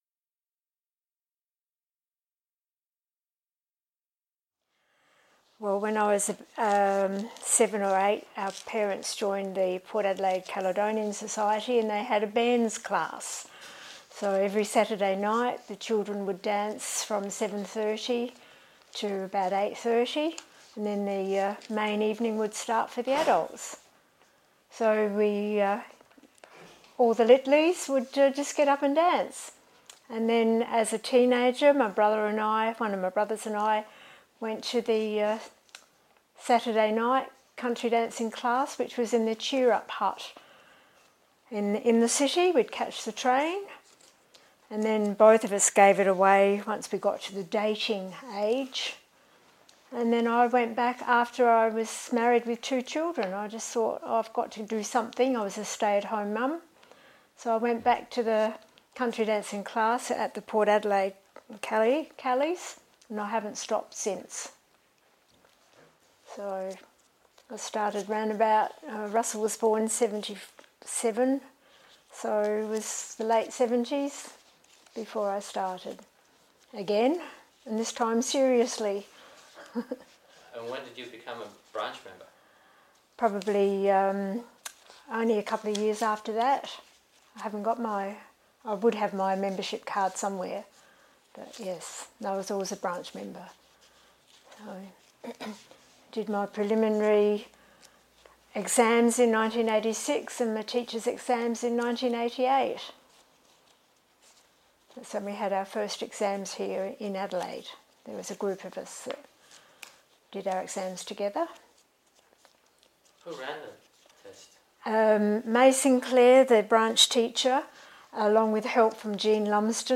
Video Interview